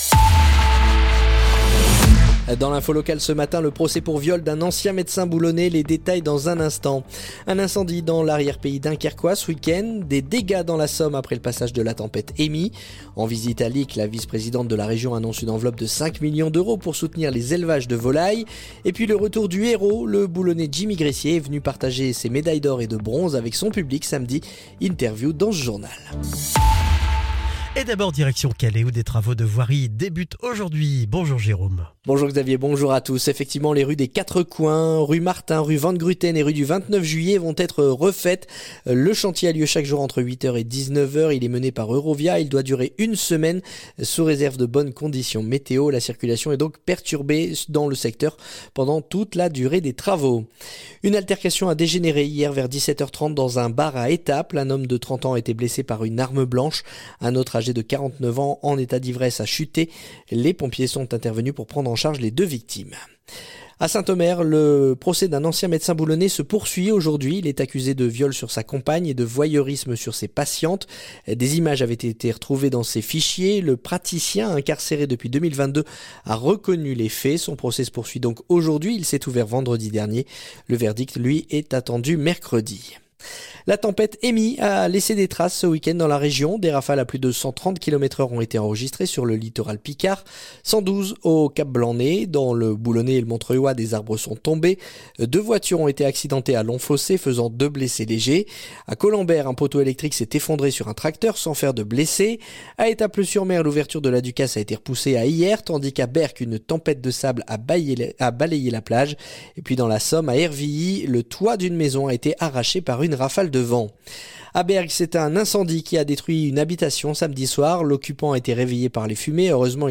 Le journal du lundi 6 octobre